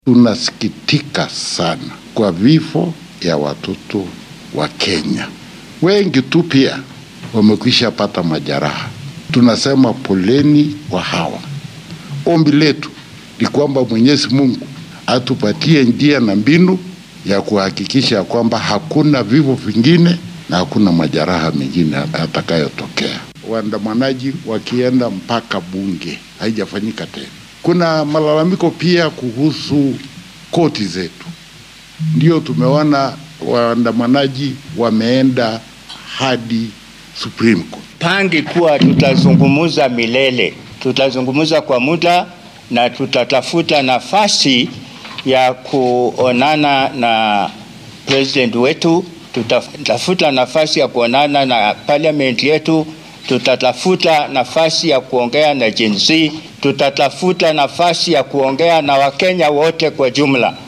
Siyaasiyiintan hore oo muujinaya walaacooda iyo waxyaabaha u qorsheysan ayaa yiri.